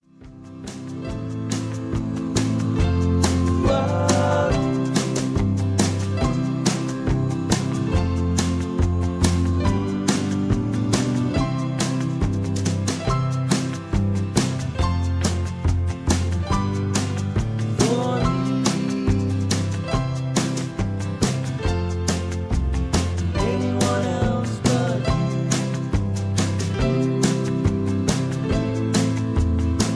Key-C) Karaoke MP3 Backing Tracks
Just Plain & Simply "GREAT MUSIC" (No Lyrics).